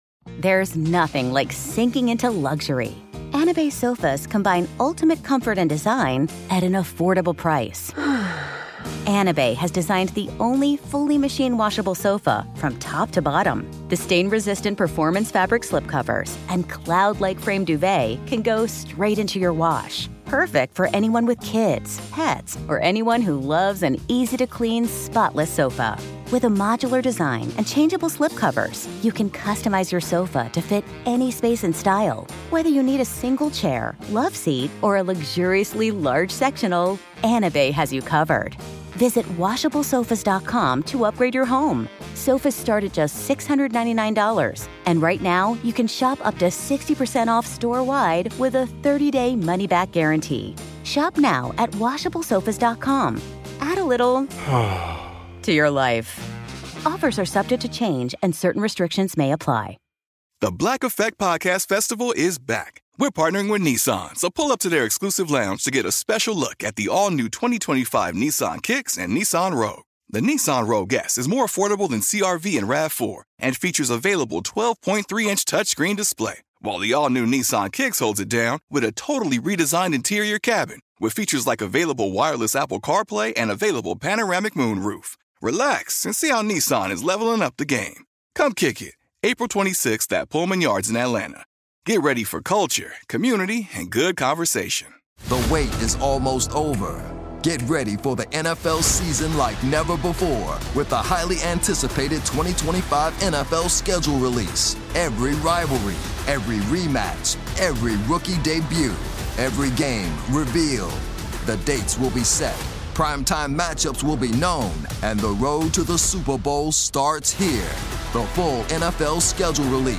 Here's the late Ambrose himself with the story of our 34th president.